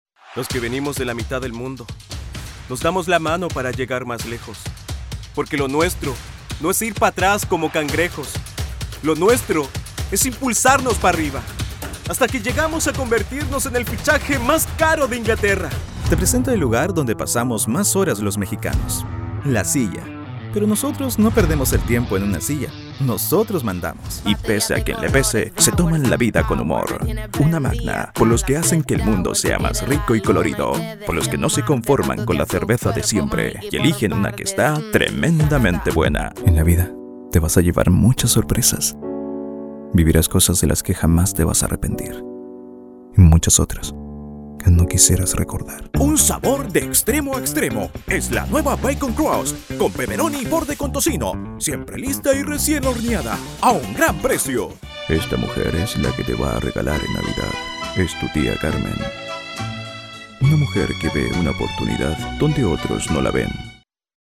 Espanhol (Chileno)
Esquentar
Conversacional
Confiante